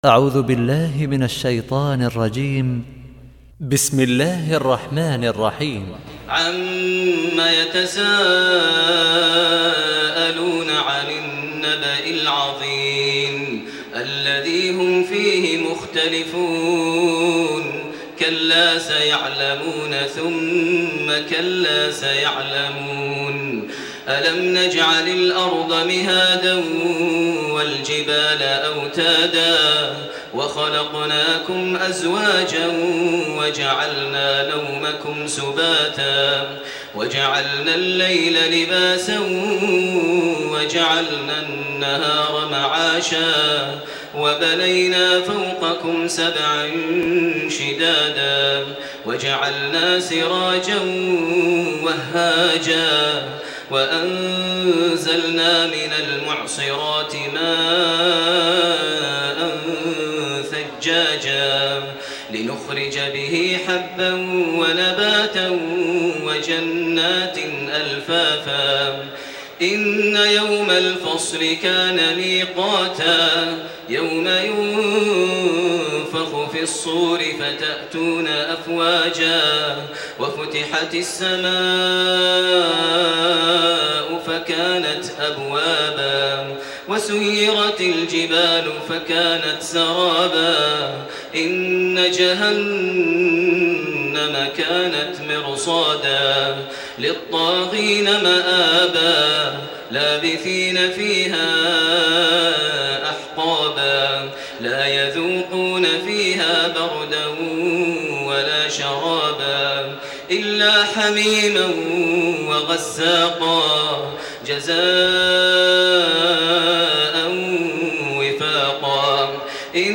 تراويح ليلة 29 رمضان 1428هـ من سورة النبأ الى الليل Taraweeh 29 st night Ramadan 1428H from Surah An-Naba to Al-Lail > تراويح الحرم المكي عام 1428 🕋 > التراويح - تلاوات الحرمين